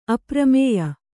♪ apramēya